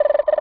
cartoon_electronic_computer_code_04.wav